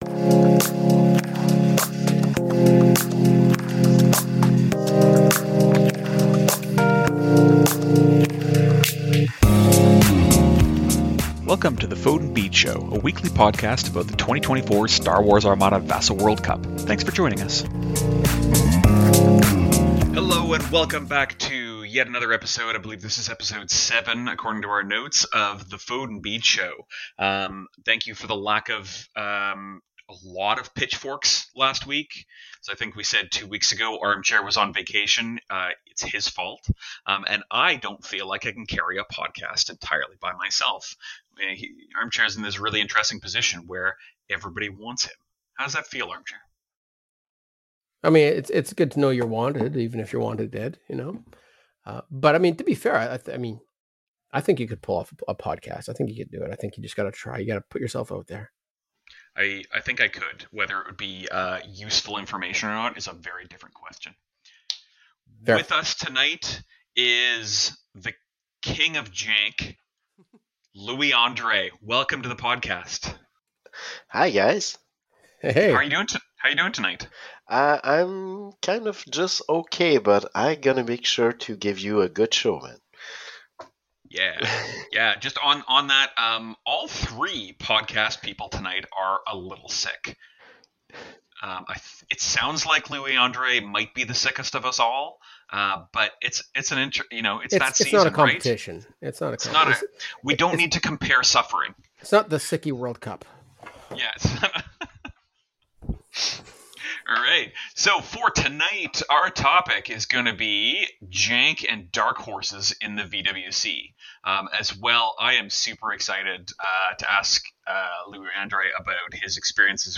All 3 are sick, but all 3 give a good podcast go anyway.